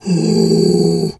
bear_pain_whimper_08.wav